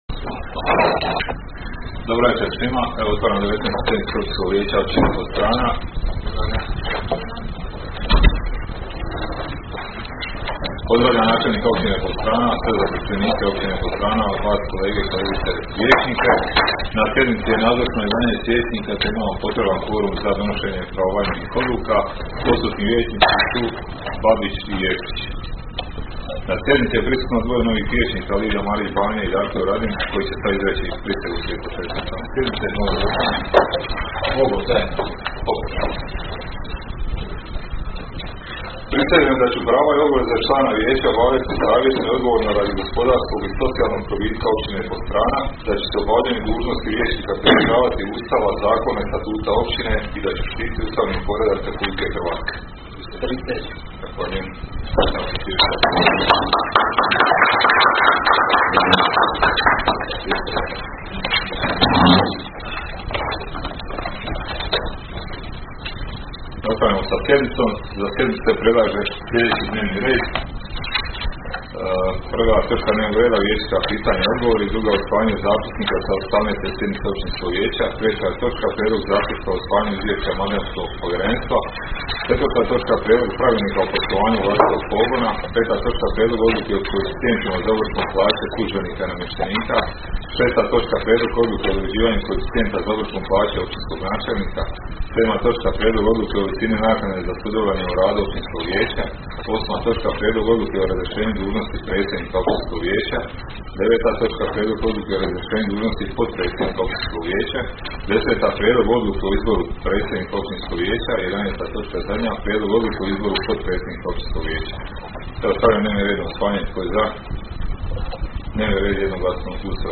Sjednica će se održati dana 19. srpnja (srijeda) 2023. godine u 19,00 sati u vijećnici Općine Podstrana.